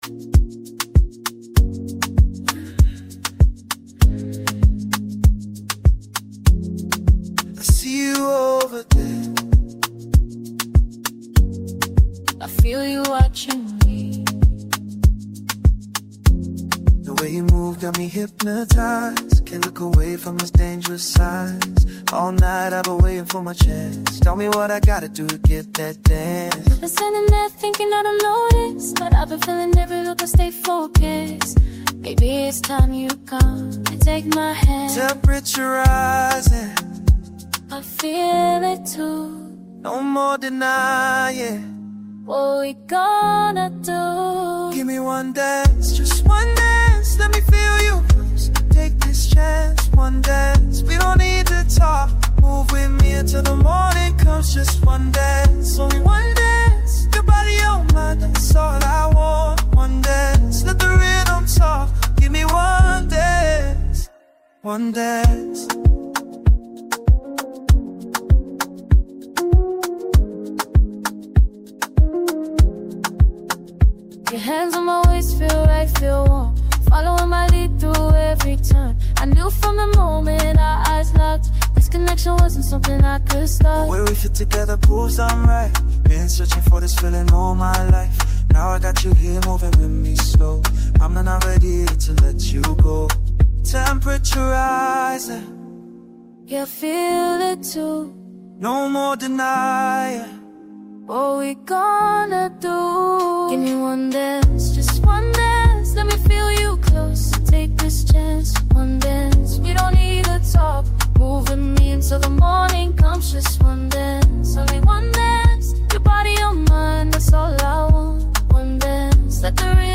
smooth, infectious Afro-pop record